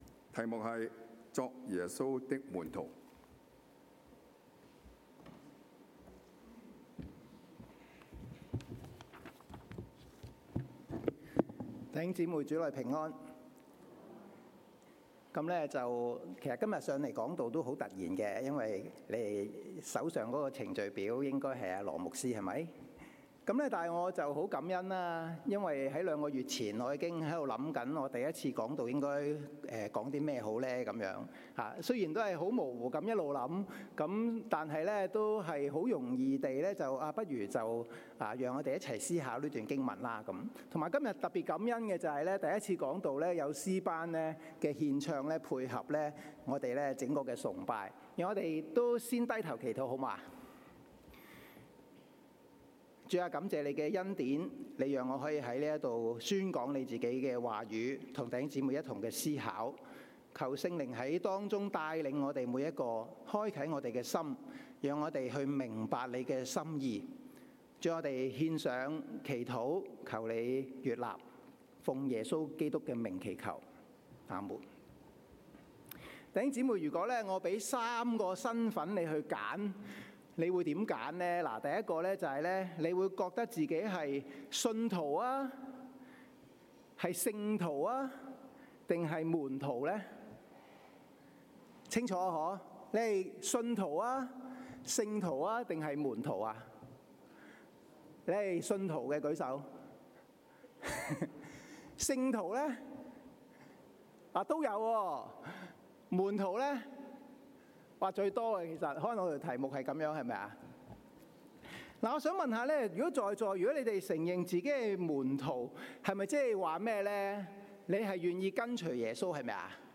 Sermons English - The Chinese Christian Church